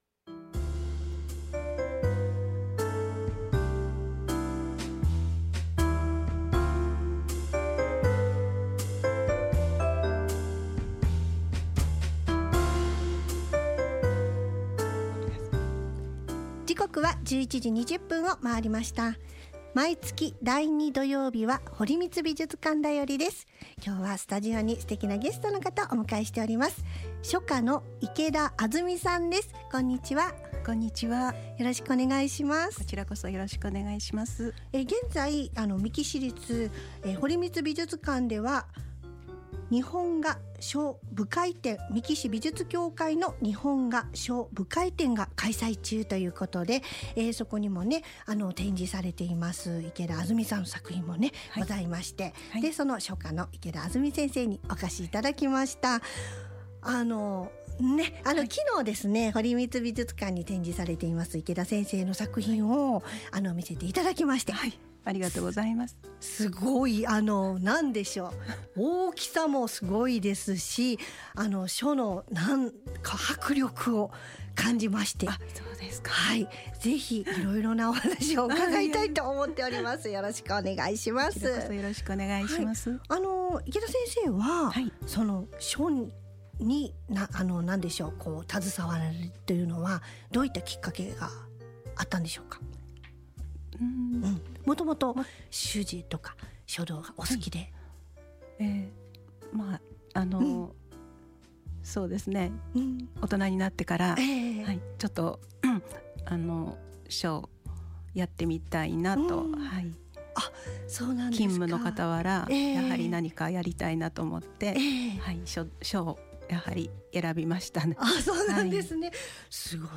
5月10日（土曜）のエフエムみっきぃ堀光美術館だよりに